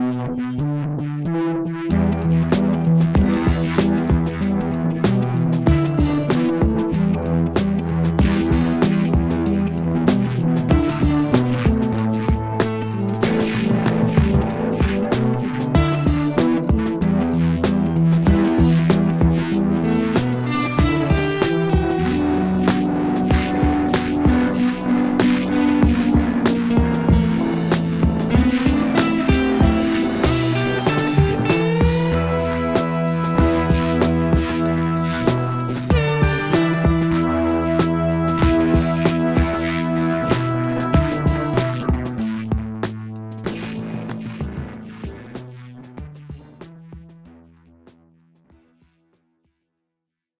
As a little update, I ended up going with the Chroma Console over the HX effects for my first pedal and I’m having a damn blast.
Somehow ended up in a very Black Keys if they were hired to write a 007 zinger realm here, and I’m not sure how I got here because this isn’t my usual thing.
Such a vibe.